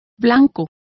Complete with pronunciation of the translation of white.